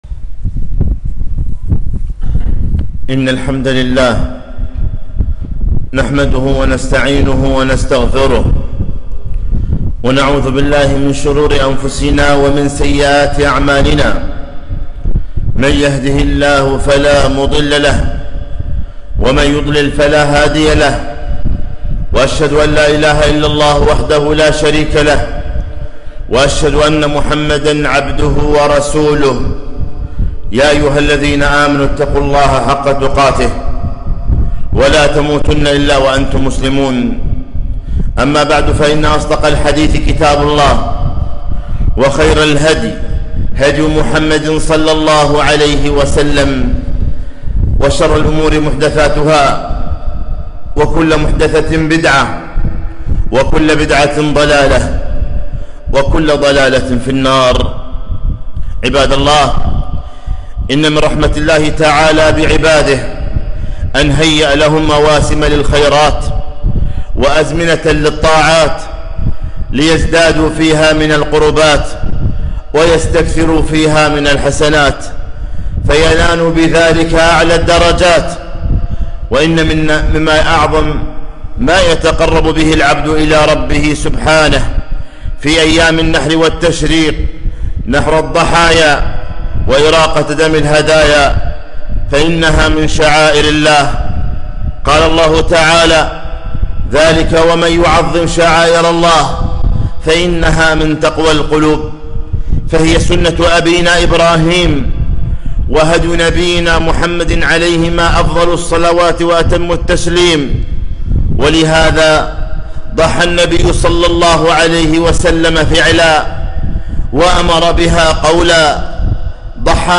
خطبة - أحكام الأضحية